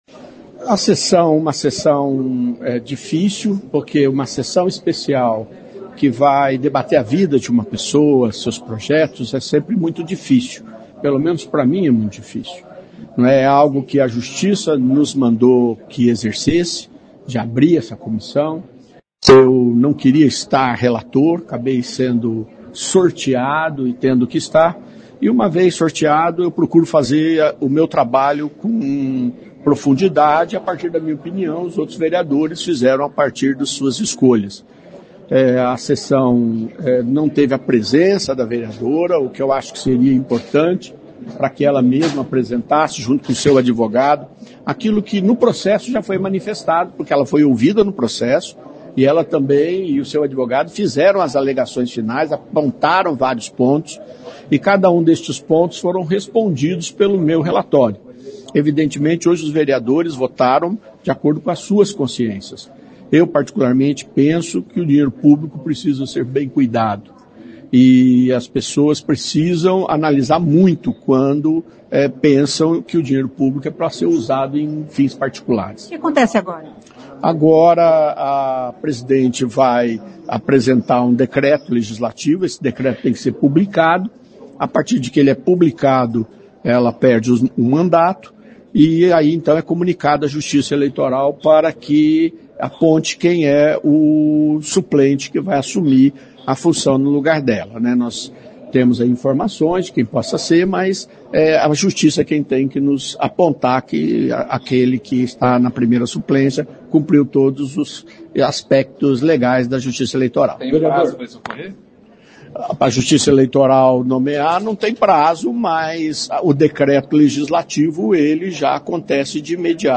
Ouça o que diz o relator da CP, vereador Sidnei Telles.